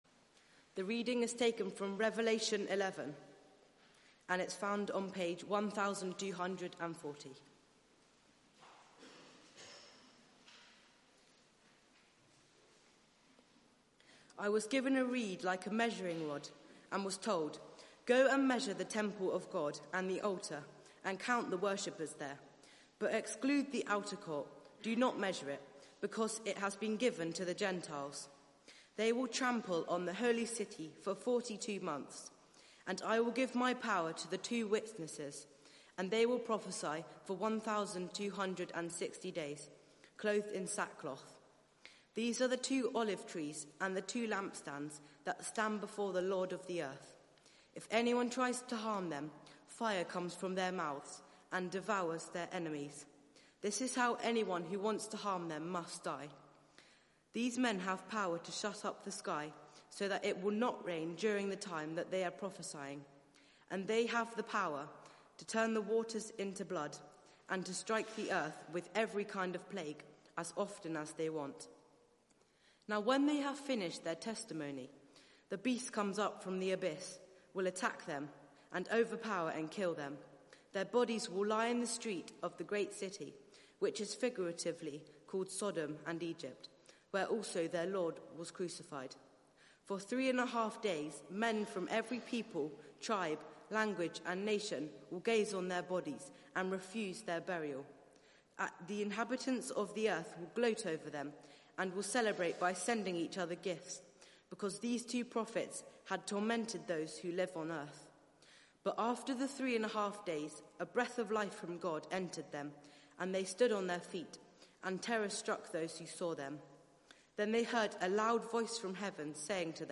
Media for 4pm Service on Sun 14th Jul 2019 16:00 Speaker
Series: The Lamb Wins Theme: God's word is unstoppable Sermon Search the media library There are recordings here going back several years.